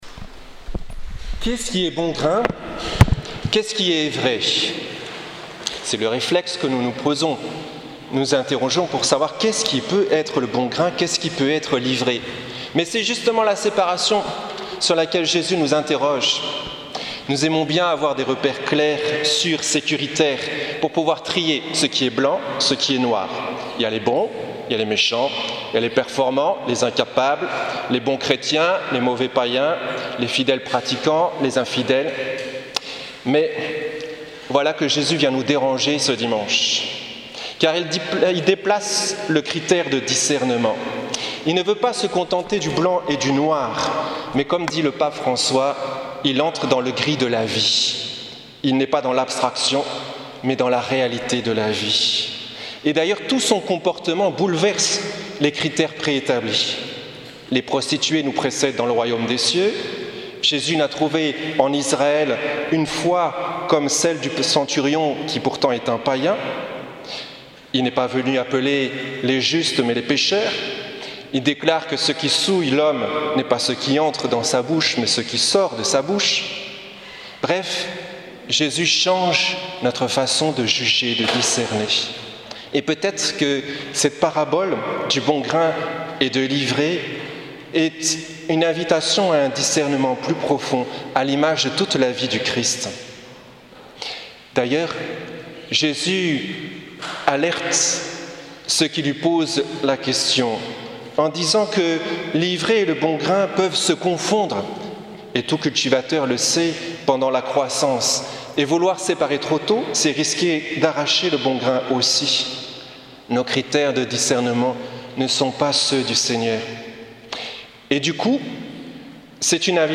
Homélie dimanche 23 juillet 2017 | Les Amis du Broussey